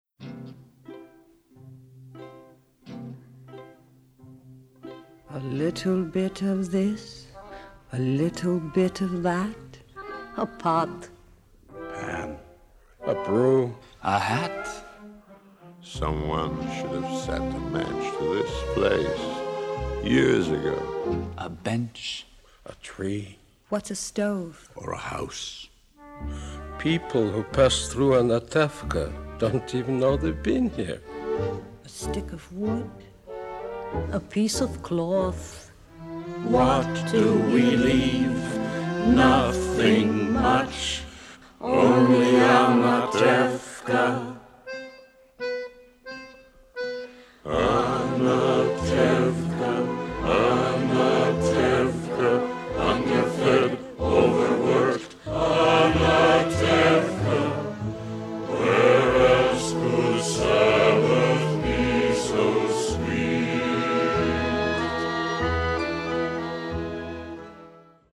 virtuoso violin soloist